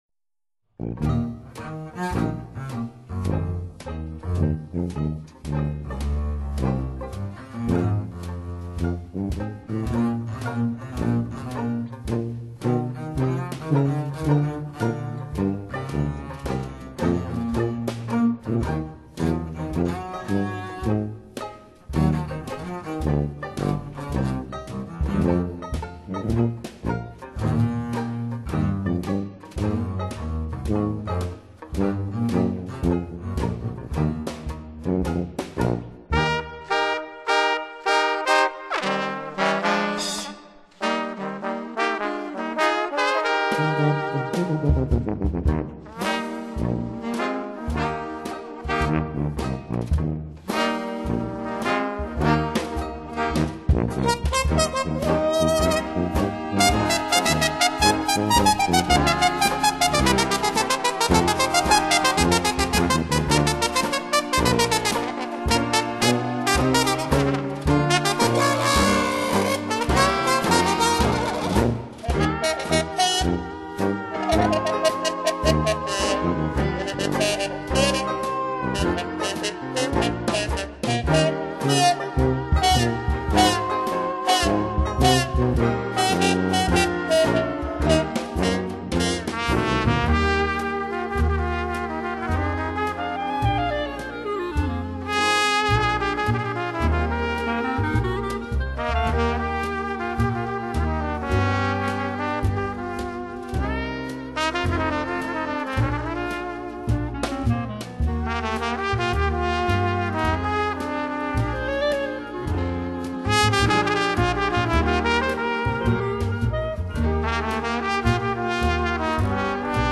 【發燒老爵士】